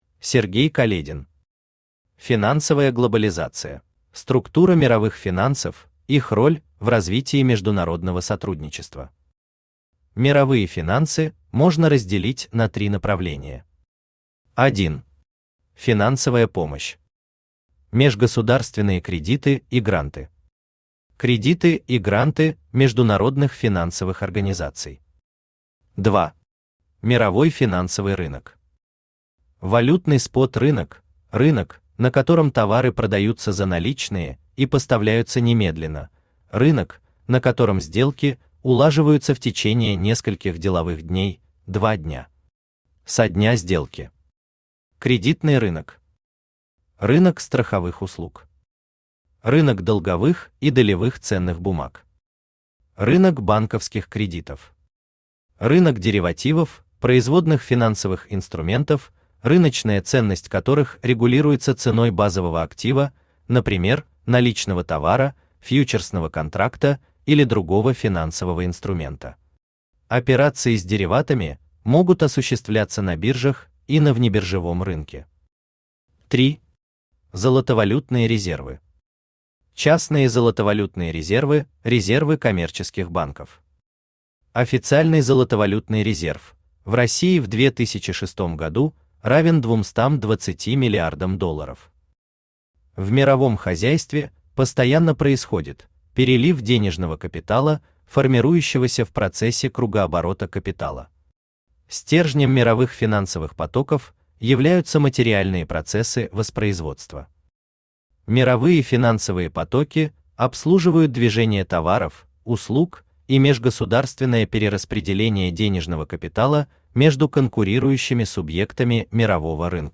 Аудиокнига Финансовая глобализация | Библиотека аудиокниг
Aудиокнига Финансовая глобализация Автор Сергей Каледин Читает аудиокнигу Авточтец ЛитРес.